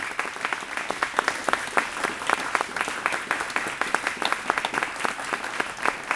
crowdClapLoop.ogg